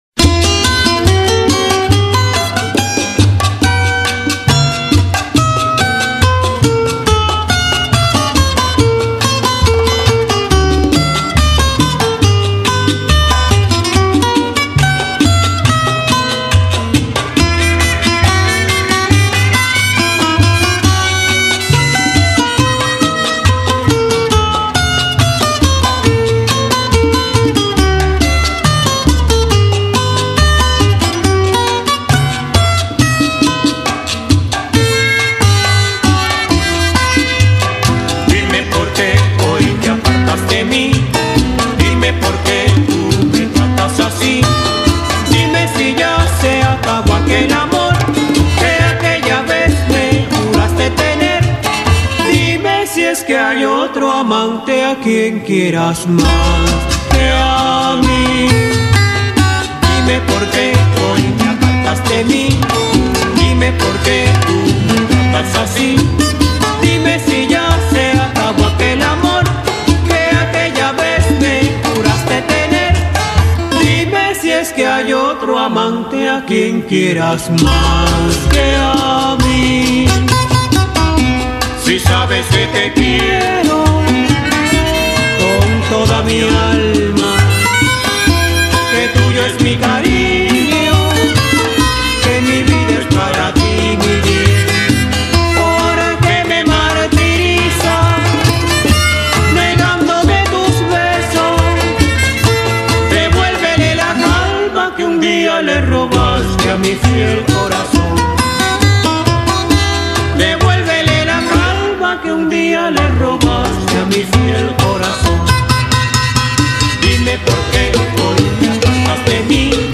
類別：世界音樂 / 美洲